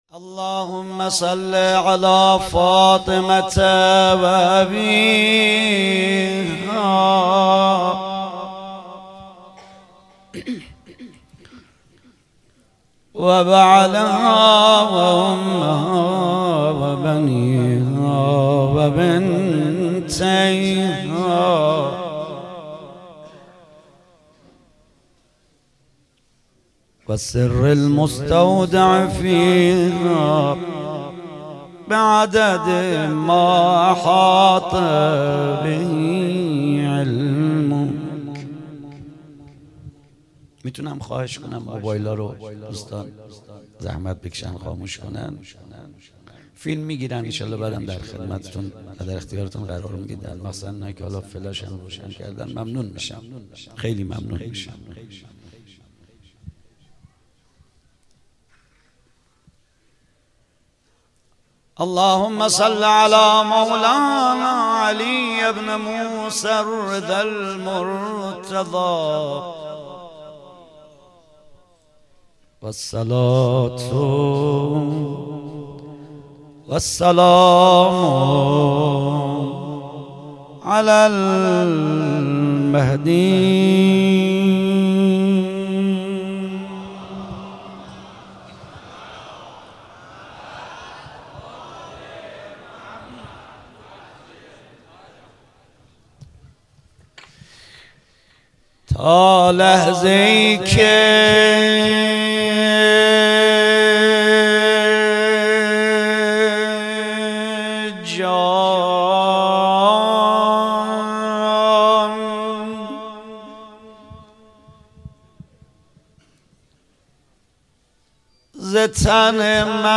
28 صفر 97 - روضه